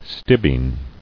[stib·ine]